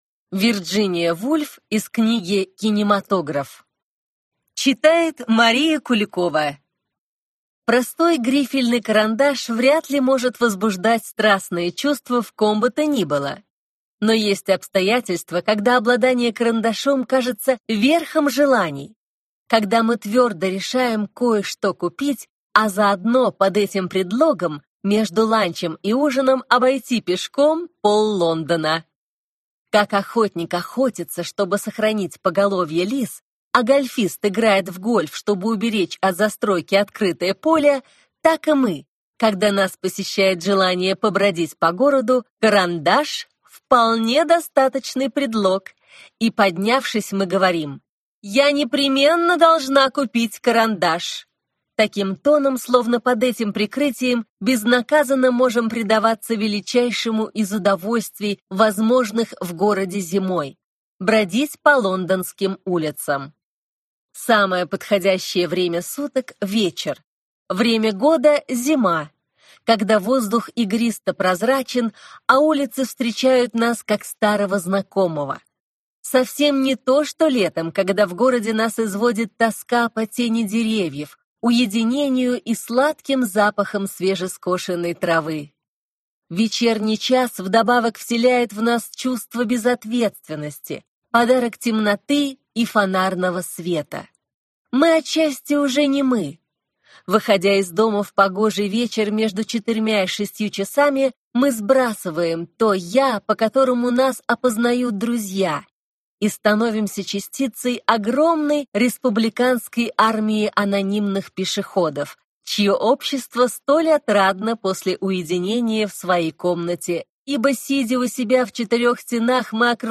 Аудиокнига Долгая прогулка: лондонское приключение | Библиотека аудиокниг